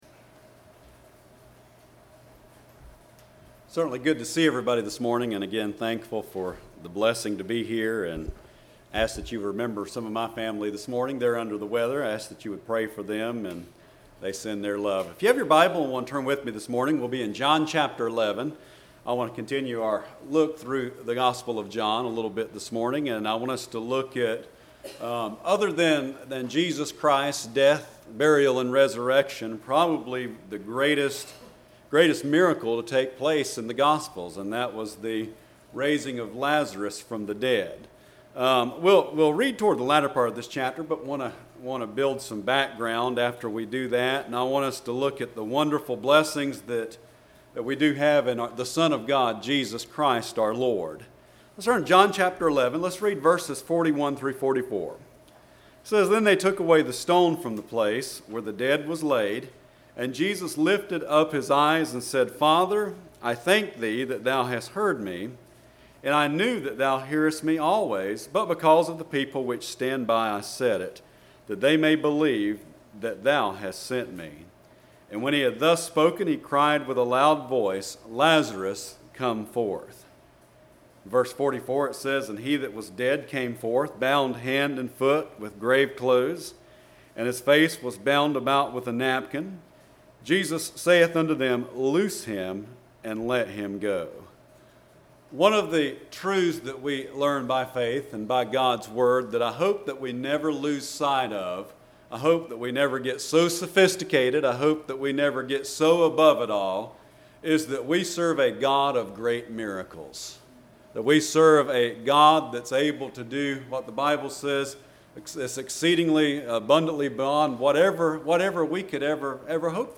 07/21/19 Sunday Morning